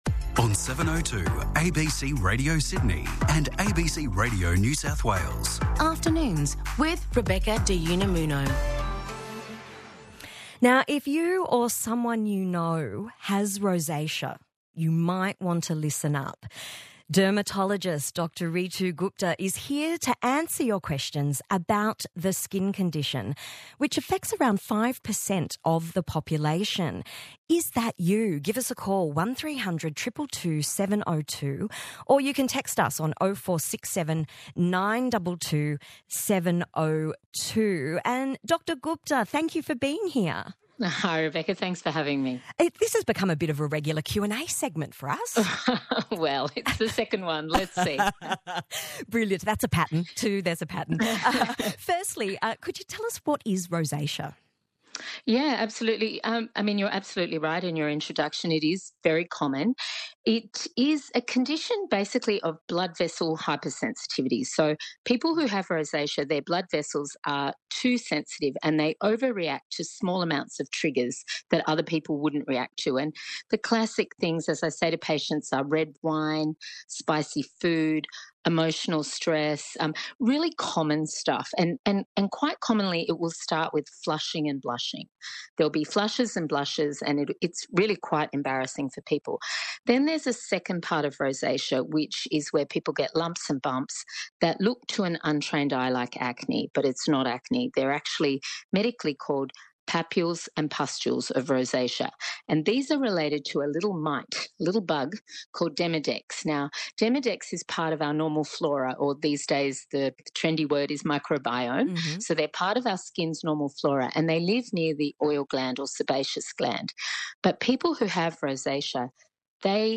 ABC-702-Radio-ROSACEA-SEGMENT.mp3